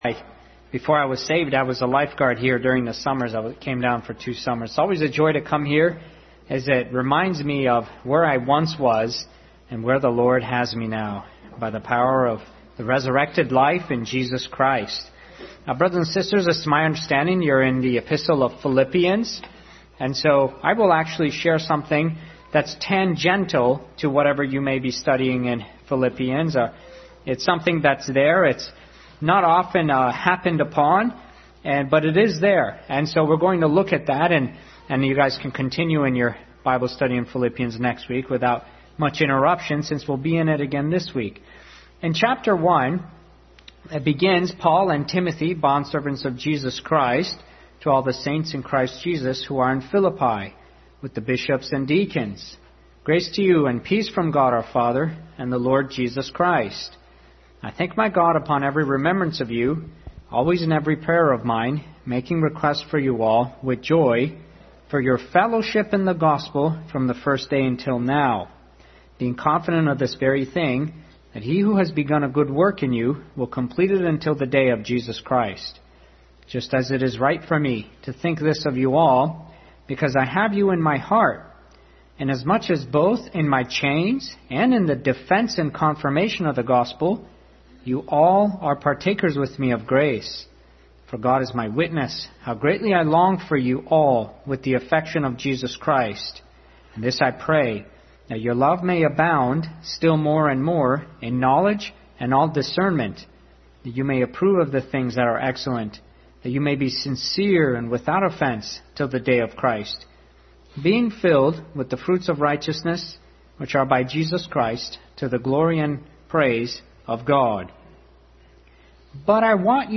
Adult Sunday School study in Philippians.